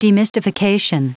Prononciation du mot demystification en anglais (fichier audio)